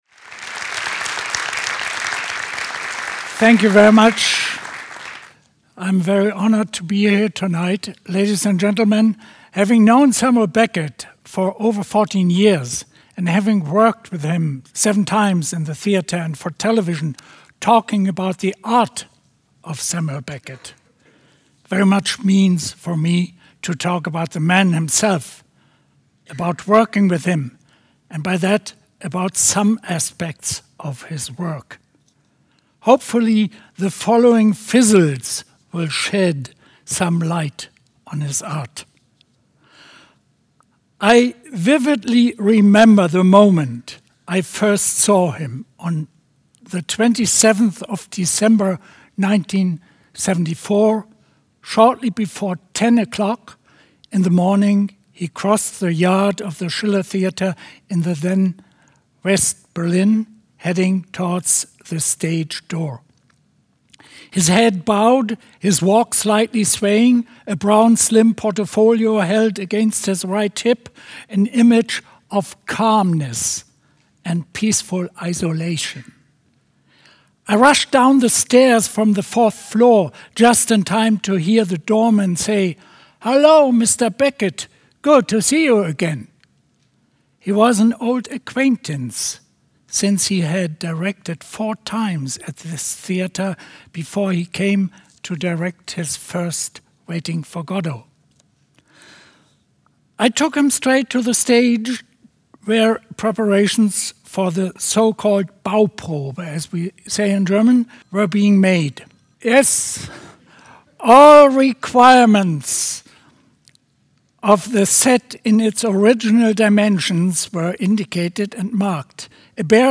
Lecture Audio Recording Available Below